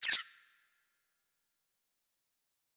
TMPerc_4.wav